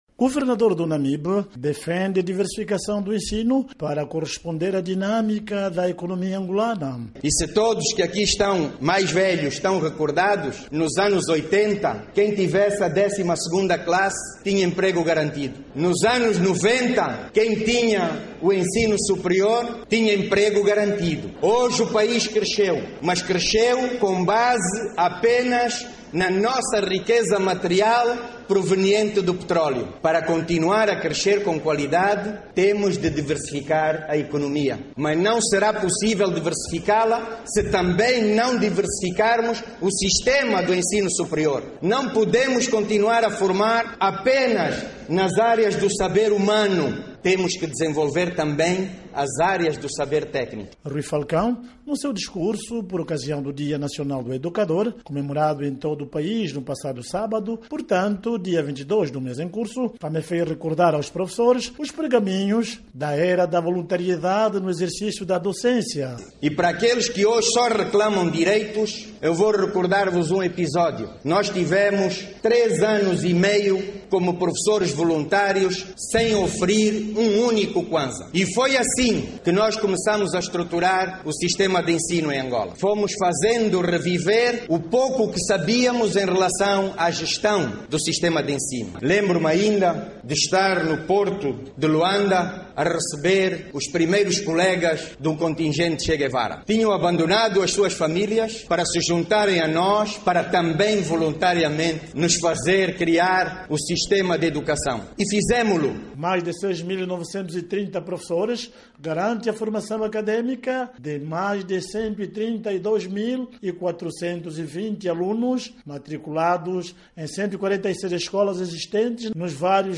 Ao falar por ocasião do dia nacional do educador no passado sábado, 22, Falcão disse que o crescimento de Angola tem tido como base “a nossa riqueza material proveniente do petróleo”.